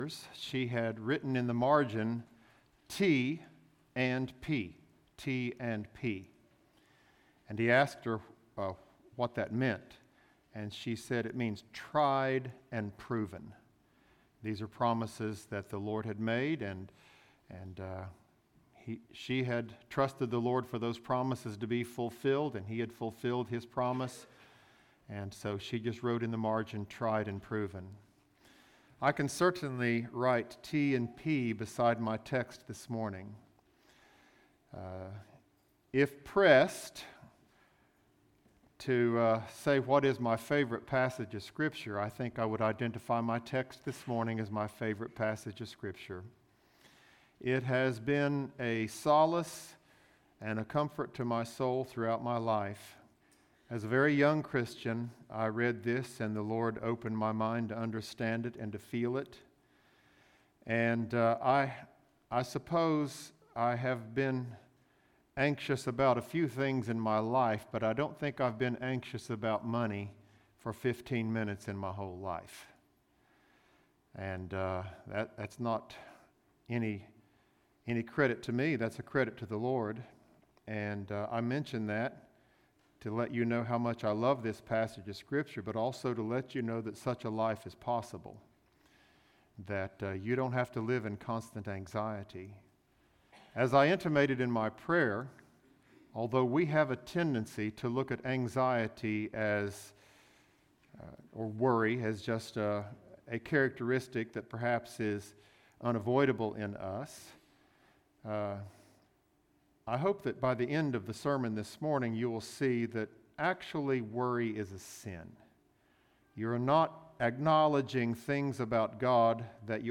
Bullitt Lick Baptist Church - Sermons